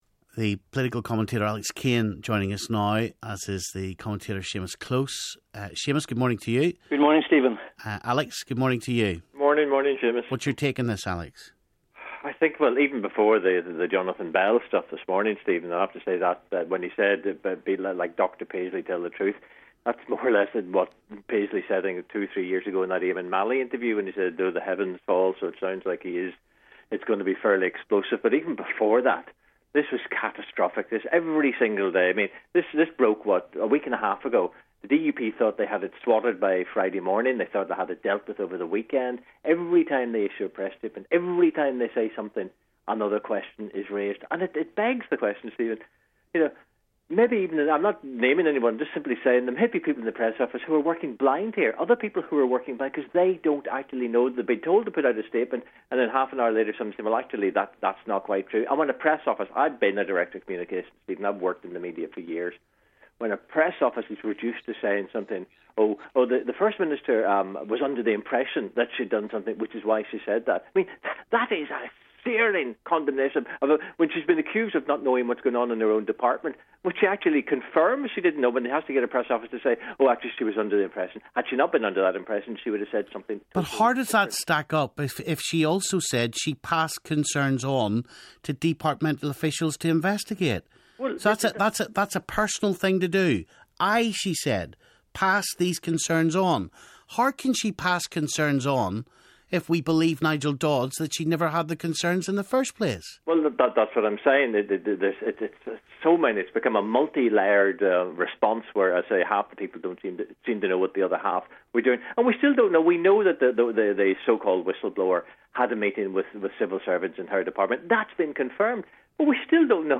Political commentators